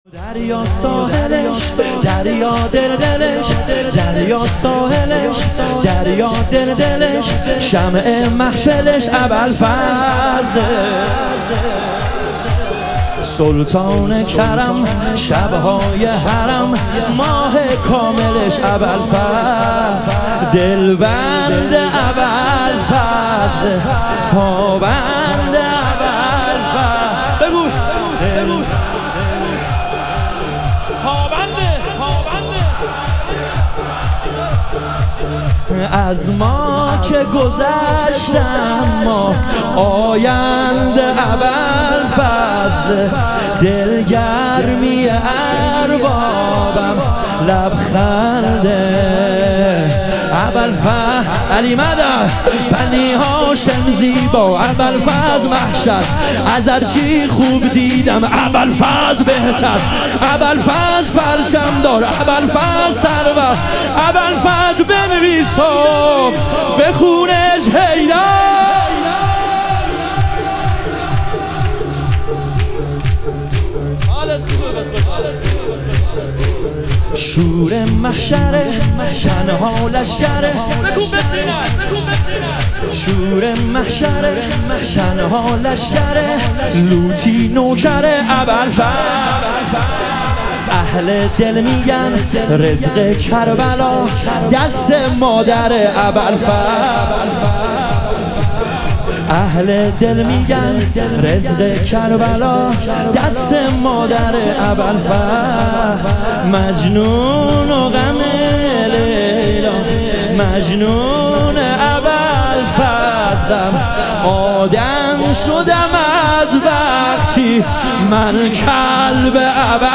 ایام فاطمیه اول - شور